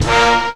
JAZZ STAB 14.wav